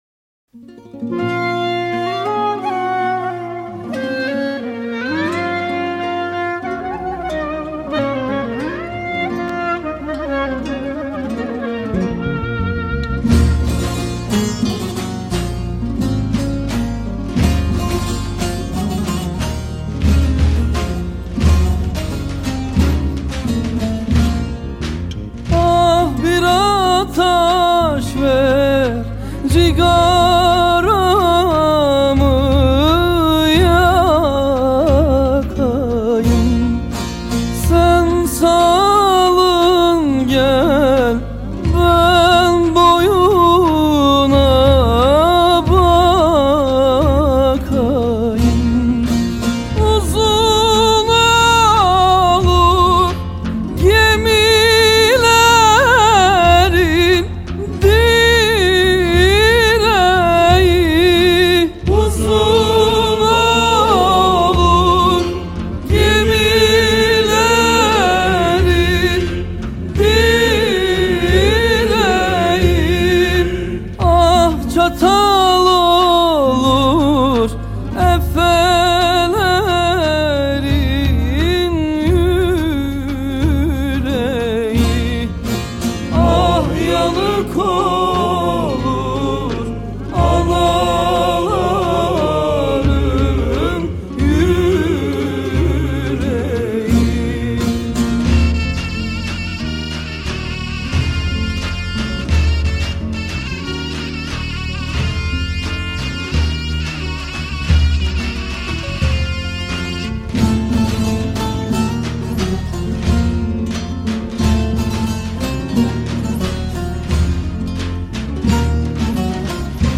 موسیقی احساسی و عربسک ترکیه‌ای